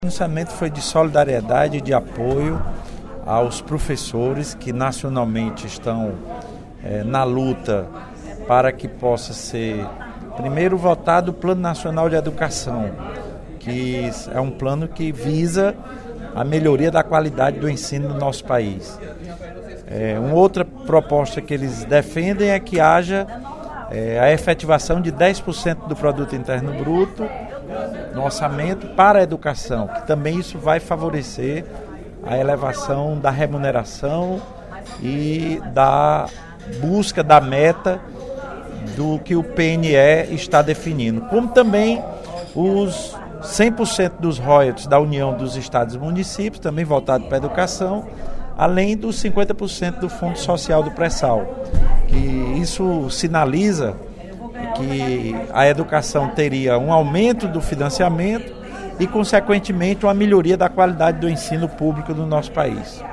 O deputado Lula Morais (PCdoB), em pronunciamento no primeiro expediente da sessão plenária desta terça-feira (18/03), externou sei apoio à greve nacional dos professores.